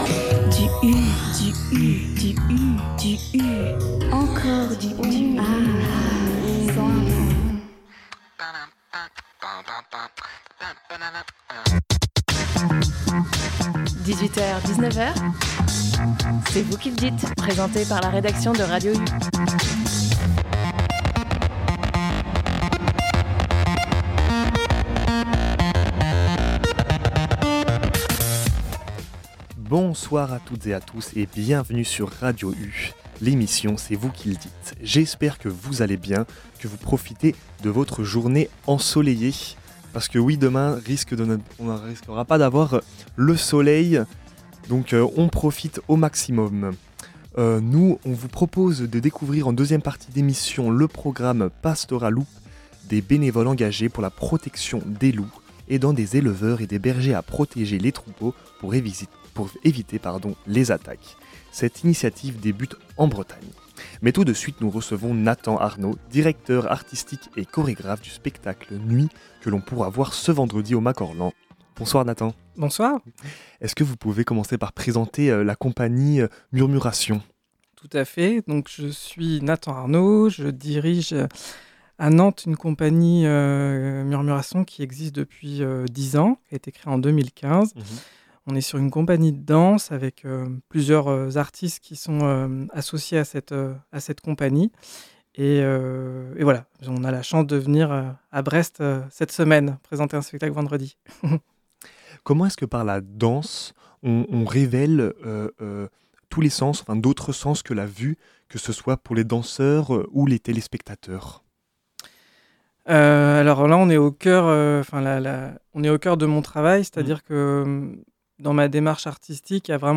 En seconde partie d’émission, une interview sur le programme PastoraLoup de l’association Ferus.